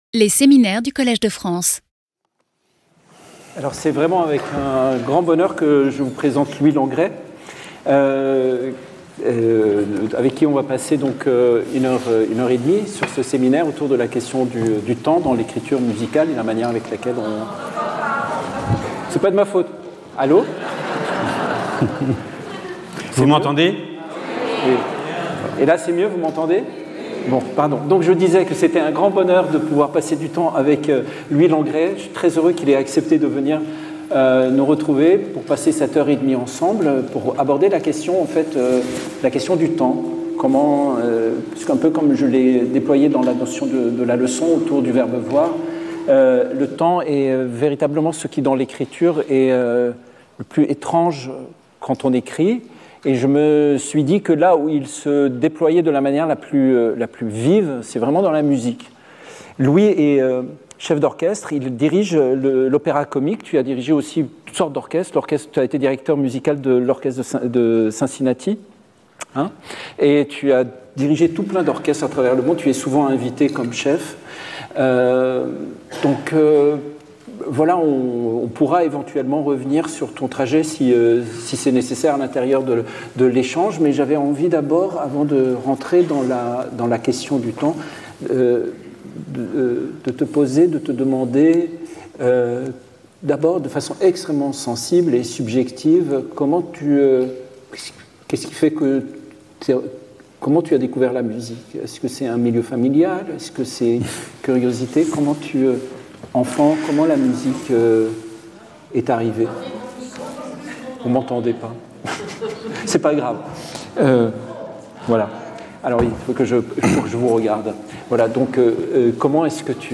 Discussion with Wajdi Mouawad.